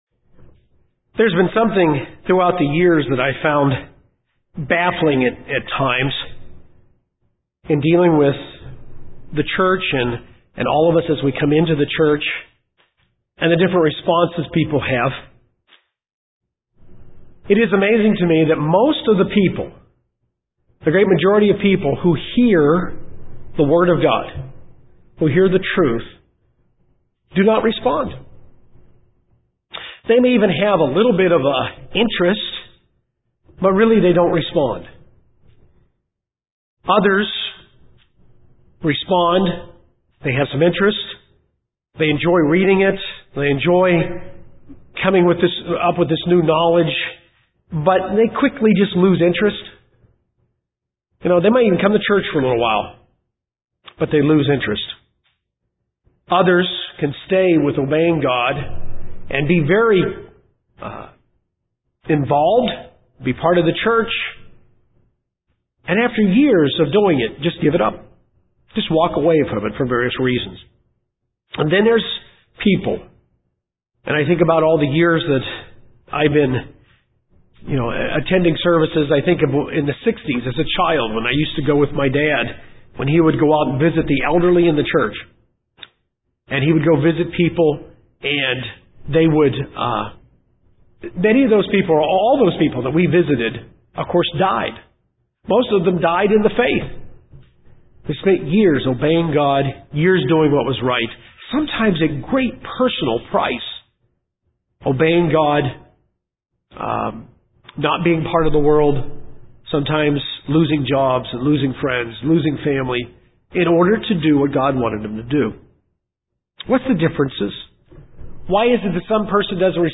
This sermon will review our calling to the Family of God and what the difference is in our responses.